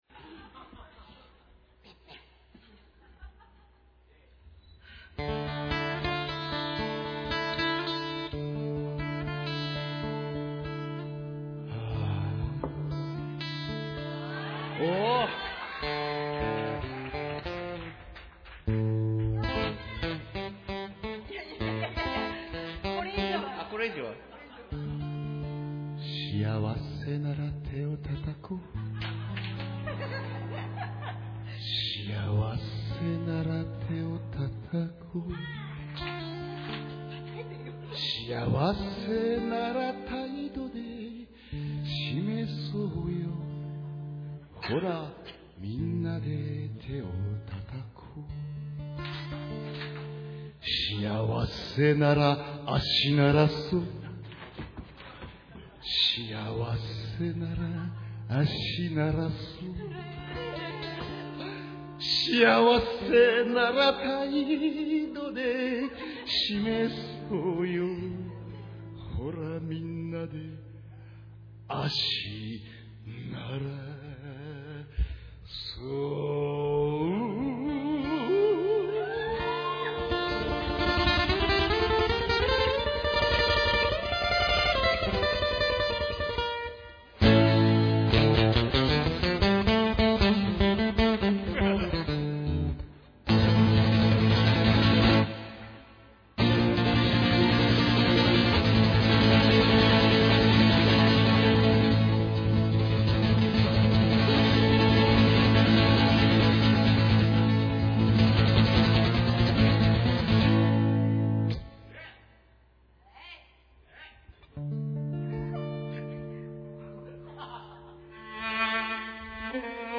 緊張する曲が多かったのでアンコールのラストは壊れぎみです。
■■■LIVE REPORT　2008/3/2■■■
*encore